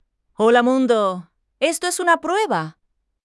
Qwen3-TTS destaca por ser hijo de una arquitectura LLM (Large Language Model) potente, lo que le permite entender el contexto antes de pronunciar la primera sílaba.